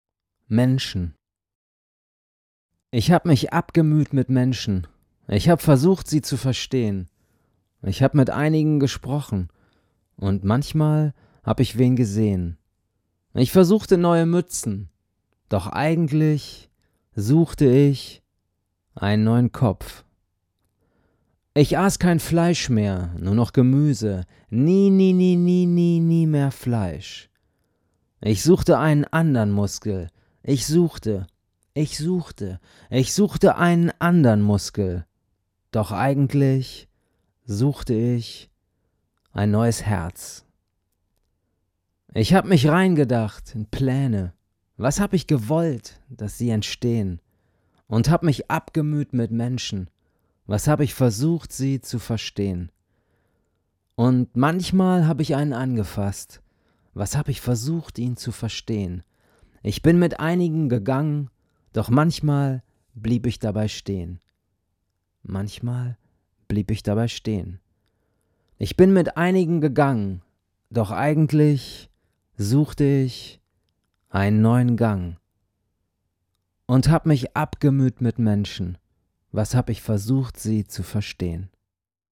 1 PeterLicht: Menschen 1:22 Play Pause 2h ago 1:22 Play Pause Später Spielen Später Spielen Listen Gefällt mir Geliked 1:22 Gelesen von PeterLicht.